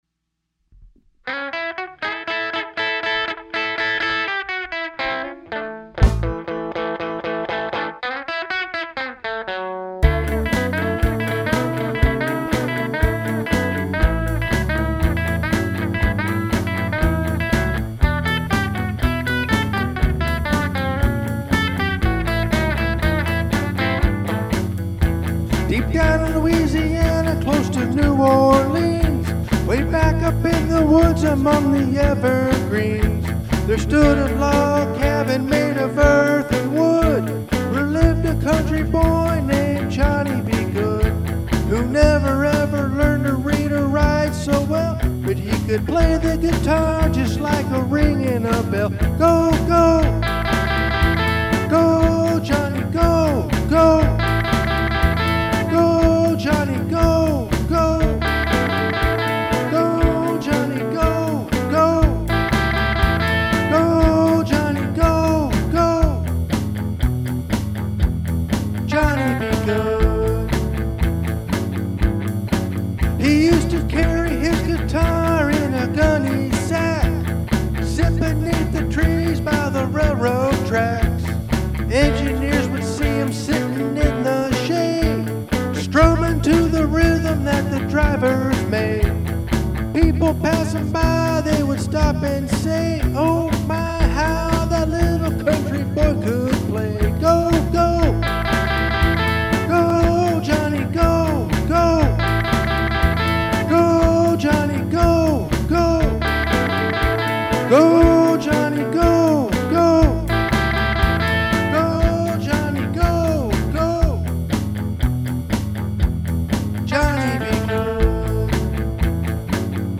I recorded all of the guitar tracks and vocals.